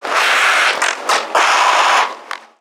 NPC_Creatures_Vocalisations_Infected [23].wav